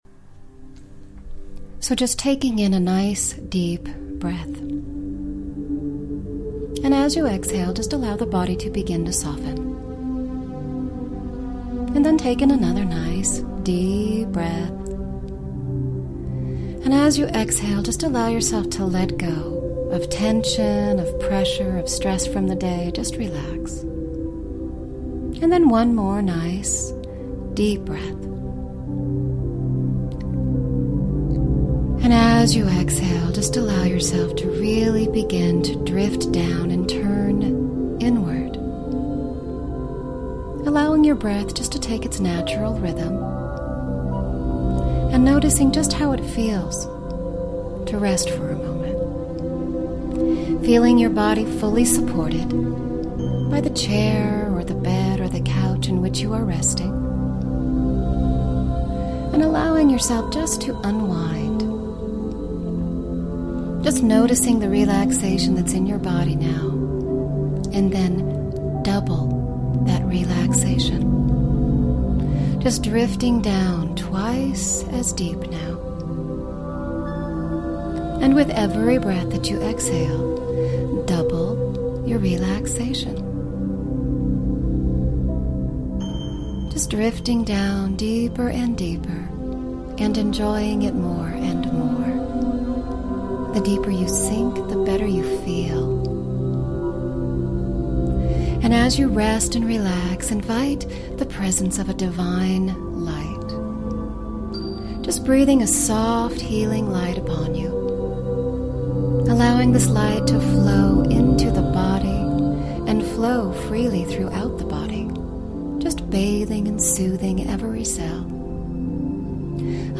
Enjoy a peaceful experience in releasing weight from within the subconscious mind. Make some time to get quiet and allow this hypnosis experience to aid in your weight loss mindset.